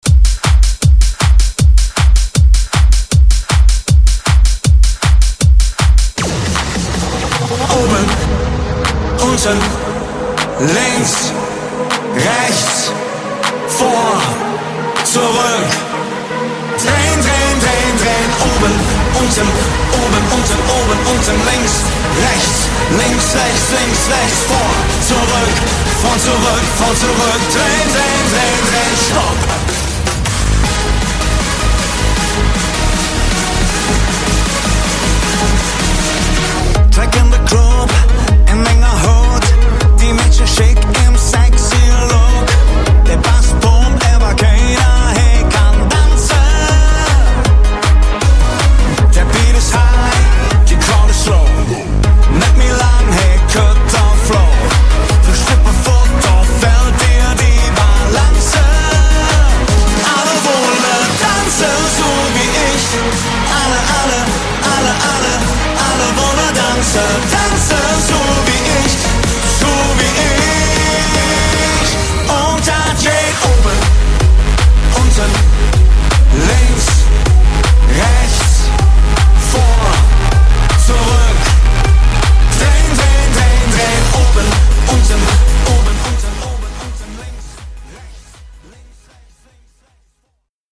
Genres: DANCE , RE-DRUM , TOP40
Clean BPM: 125 Time